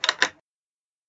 lock.mp3